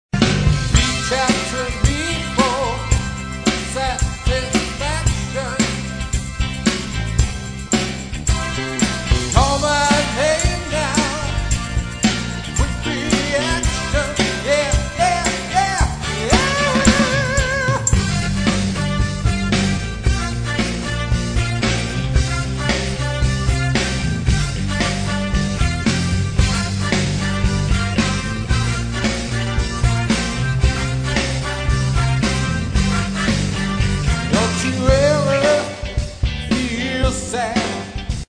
a Stax era